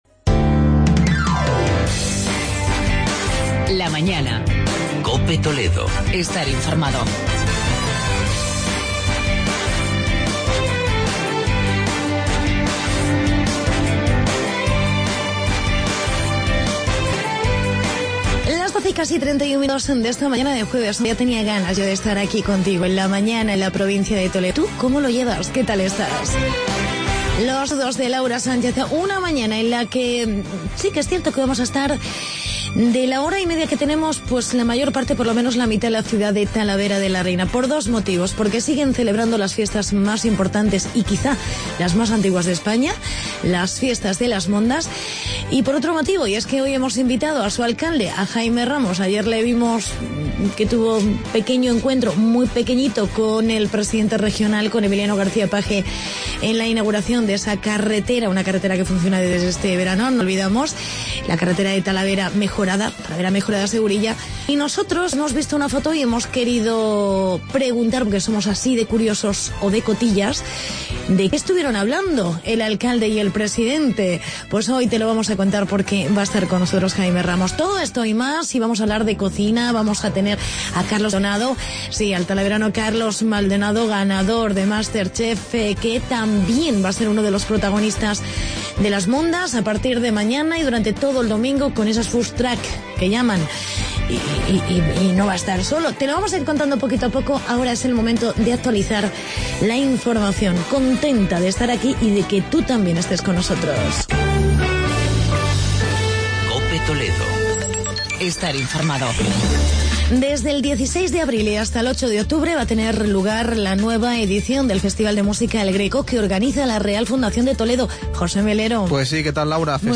Entrevista alcalde de Talavera, Jaime Ramos